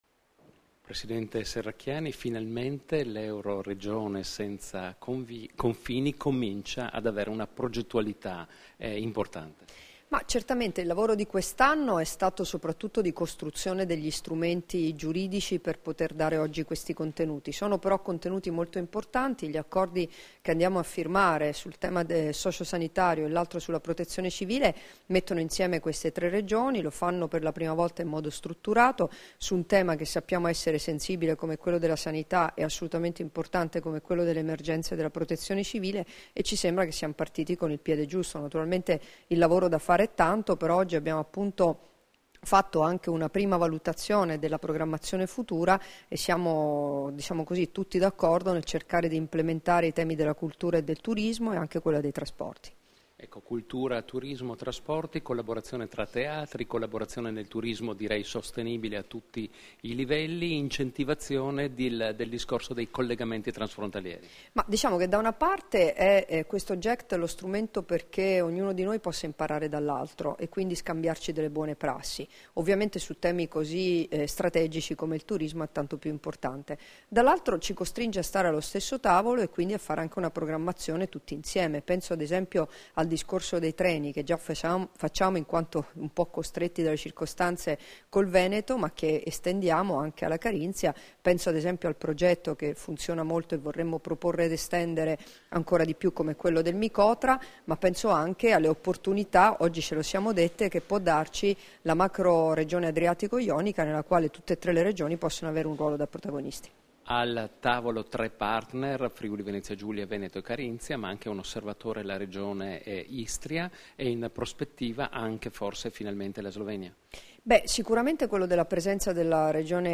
Dichiarazioni di Debora Serracchiani (Formato MP3) [2256KB]
a margine dell'Assemblea del Gruppo Europeo di Cooperazione Territoriale (GECT) "Euregio Senza Confini", rilasciate a Trieste il 22 dicembre 2014